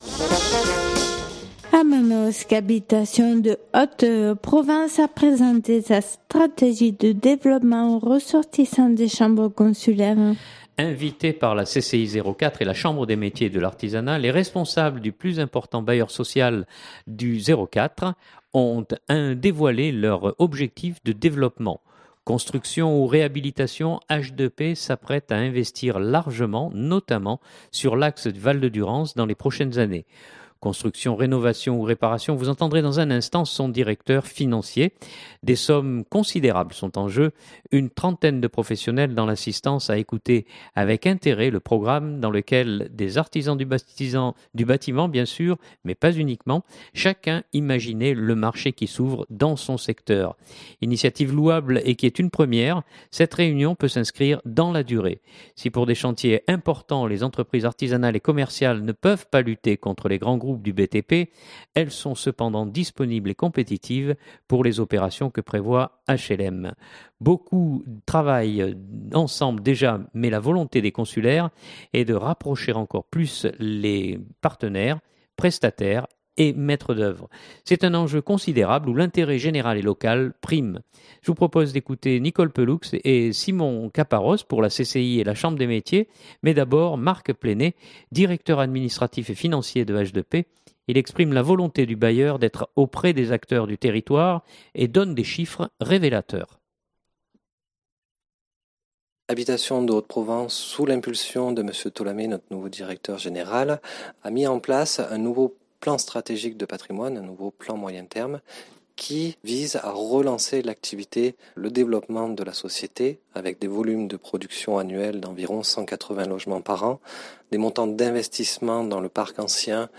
A Manosque, Habitations de Haute-Provence a présenté sa stratégie de développement aux ressortissants des chambres consulaires.